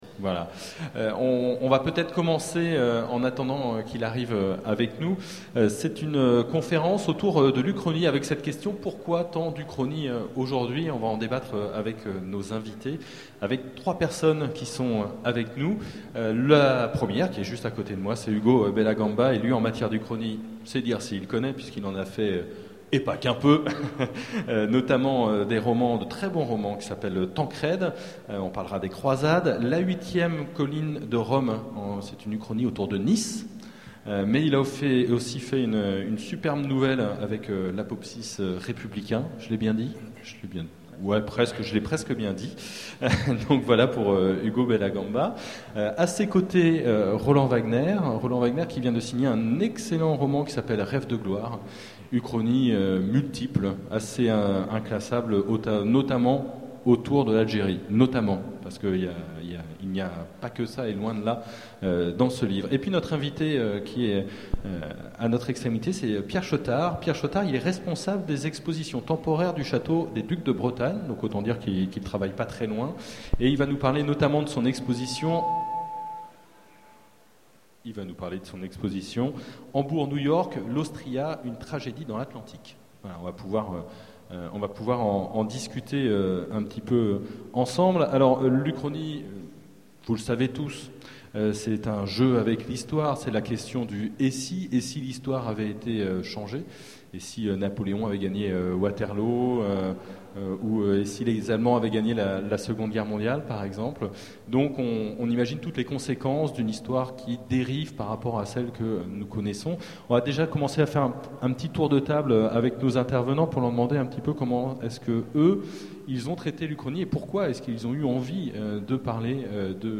Utopiales 2011 : Conférence Pourquoi tant d'uchronies